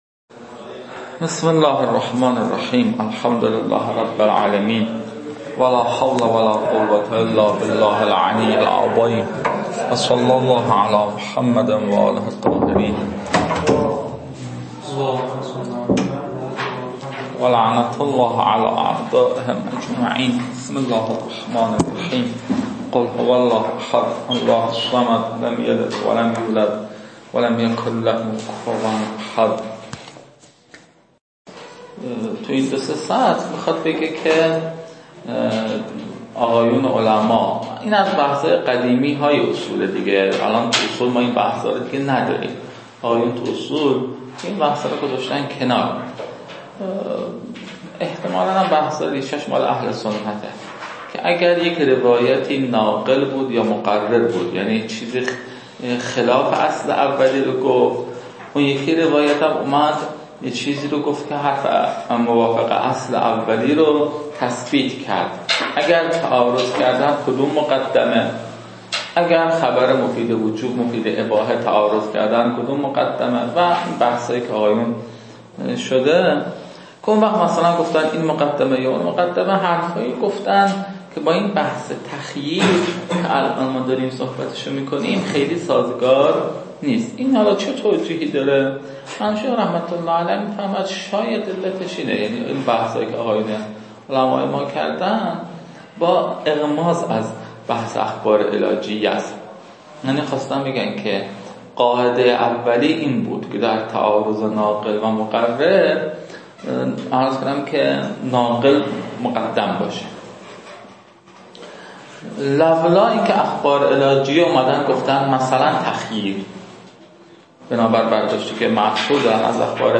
این فایل ها مربوط به تدریس مبحث برائت از كتاب فرائد الاصول (رسائل)